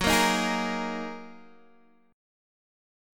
Gb6add9 chord